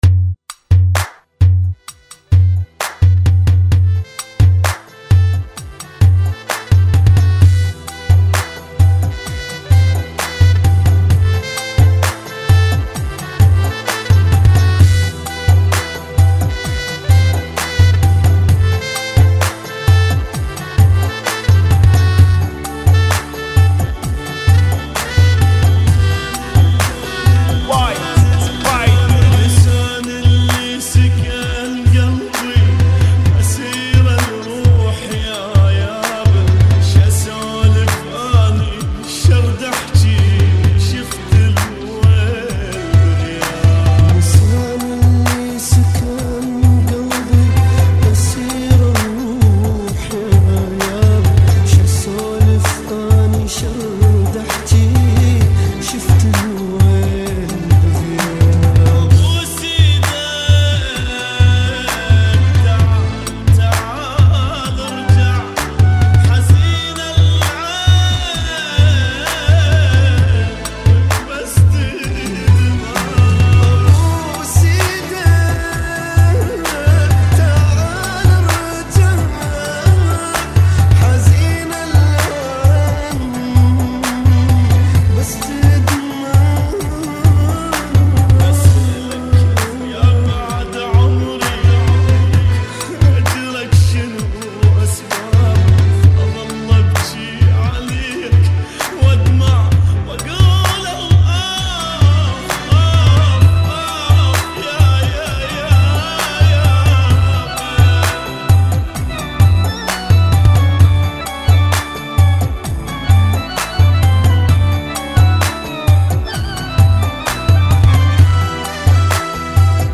Funky [ 65 Bpm ]